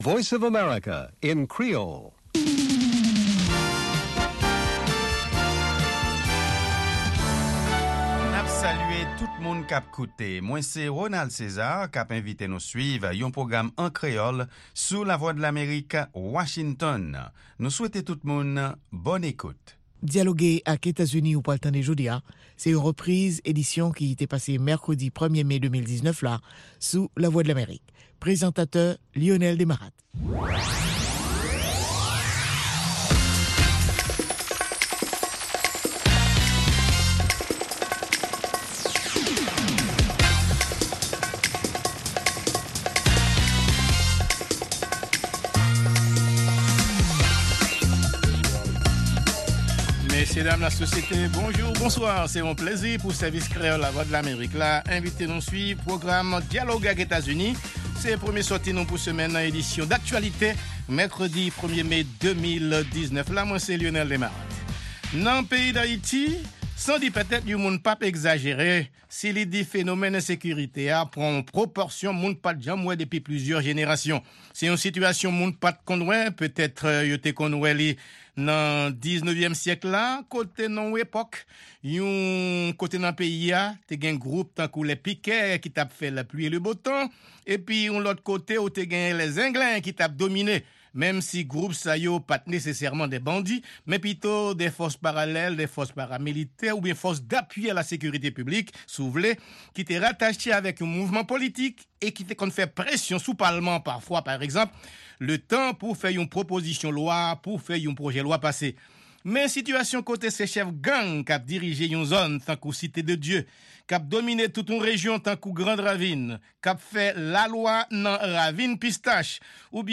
Se 2èm pwogram jounen an, avèk nouvèl tou nèf sou Lèzetazini, Ayiti ak rès mond la. 2 fwa pa semèn (mèkredi ak vandredi) se yon pwogram lib tribin "Dyaloge ak Etazini", sou Ayiti oubyen yon tèm enpòtan konsènan Lèzetazini ou rejyon Amerik Latin nan.